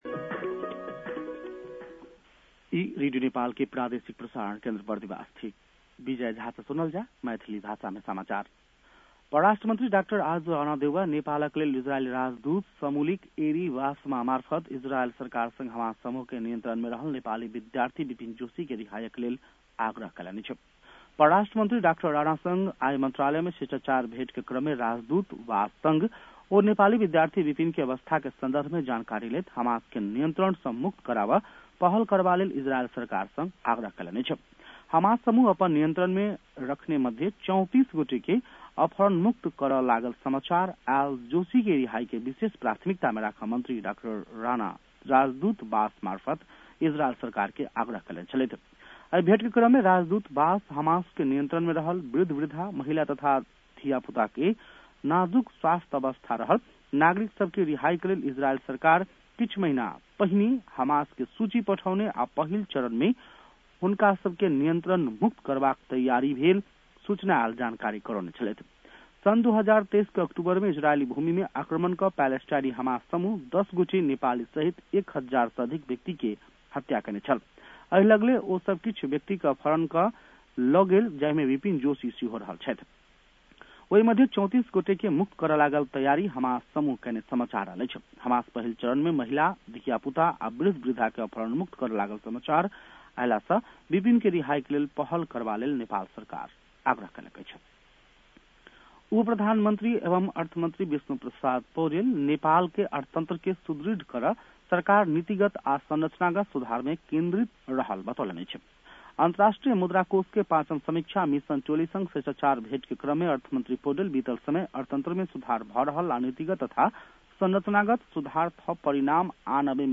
मैथिली भाषामा समाचार : २५ पुष , २०८१
Maithali-news-9-24.mp3